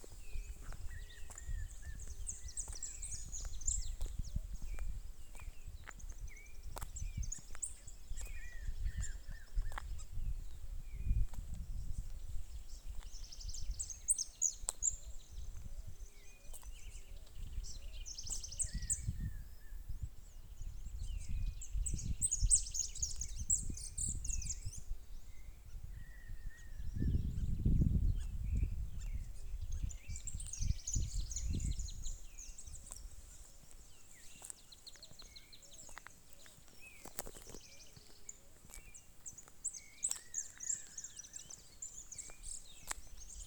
Durmilí (Nystalus maculatus)
Nombre en inglés: Spot-backed Puffbird
Condición: Silvestre
Certeza: Fotografiada, Vocalización Grabada